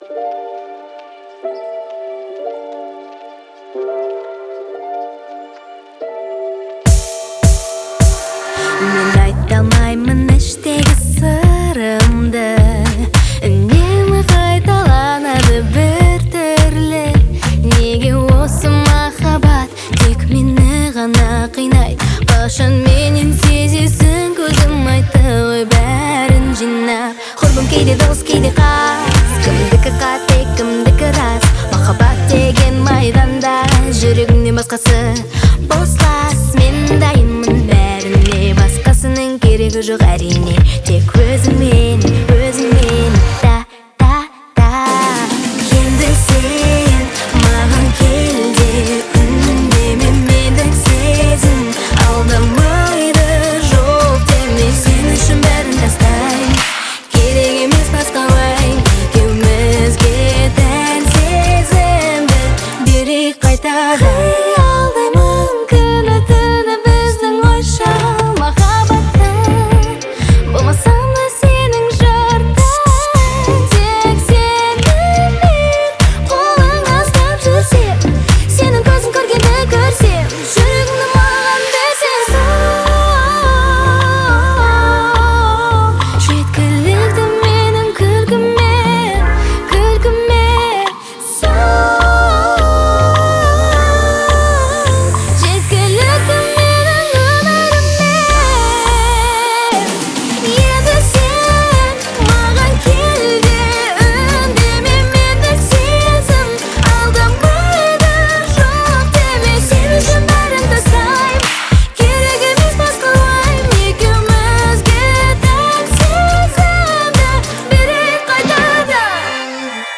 элементы хип-хопа и электронной музыки